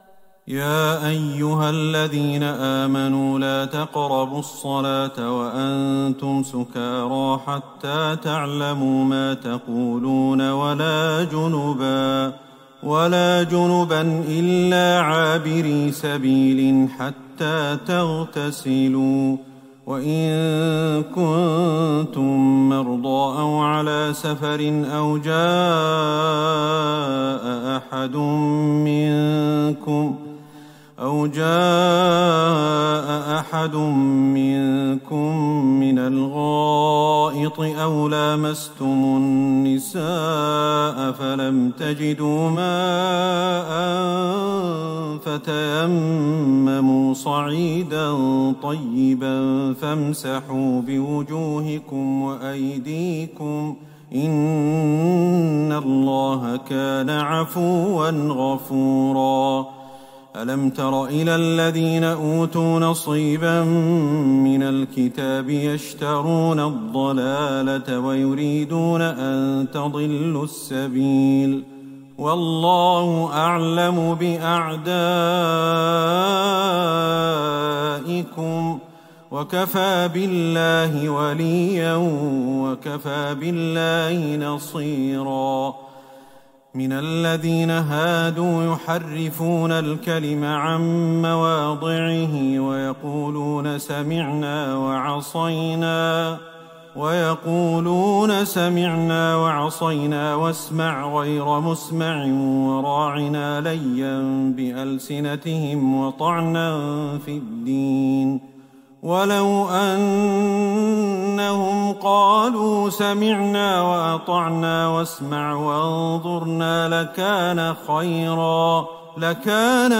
ليلة ٦ رمضان ١٤٤١هـ من سورة النساء { ٤٣-٨٧ } > تراويح الحرم النبوي عام 1441 🕌 > التراويح - تلاوات الحرمين